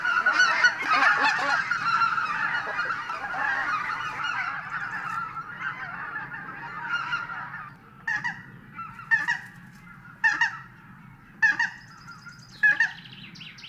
Sjöfåglarnas läten
Bläsgås
tundrahanhi.mp3